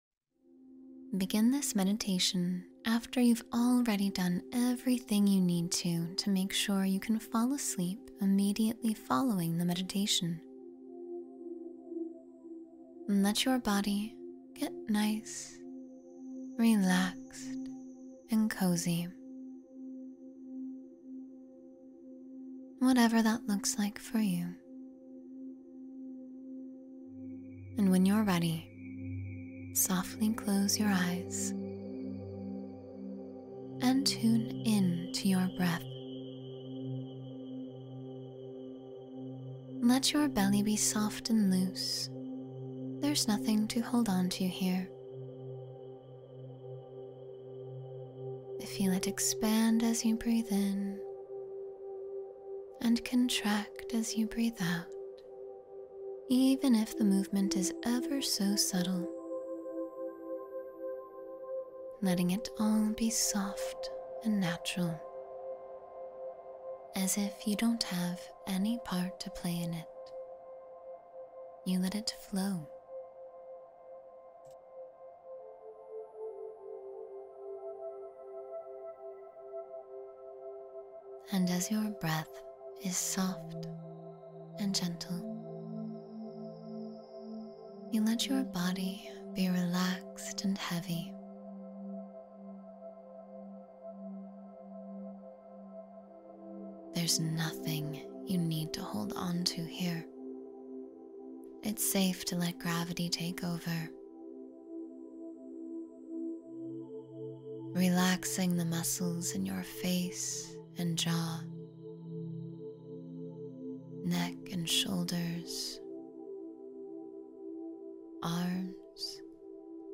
Relax Before Sleep and Find Inner Peace — Meditation for Deep Rest